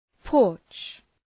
Προφορά
{pɔ:rtʃ}